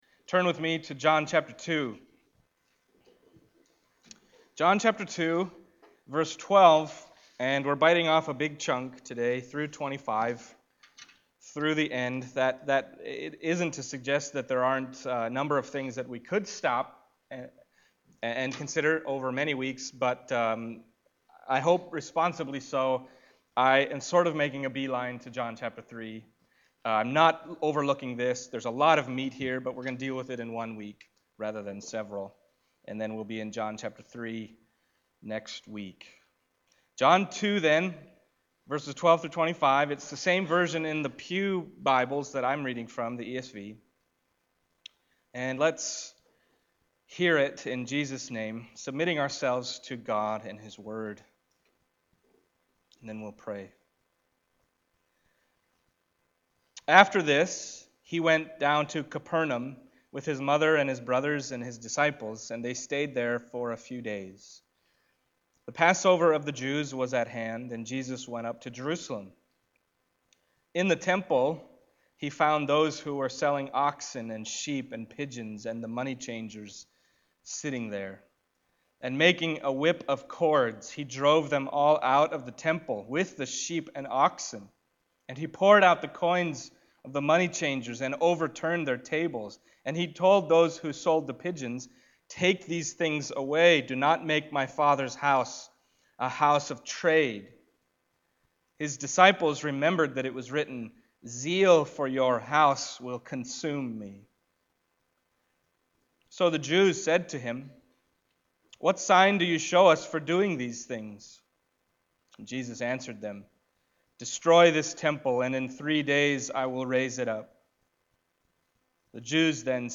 John Passage: John 2:12-25 Service Type: Sunday Morning John 2:12-25 « The Wonder of New Wine at a Wedding You Must Be Born Again